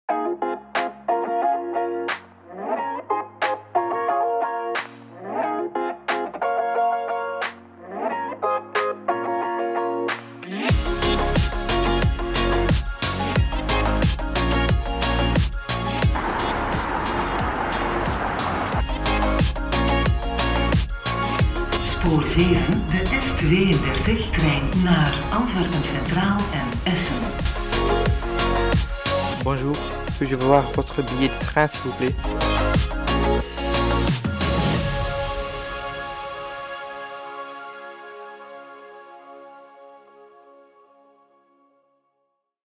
I've recorded all kinds of things, recorded,... Afterwards I edited this in Adobe Audition. 2 versions:
- Non deaf version: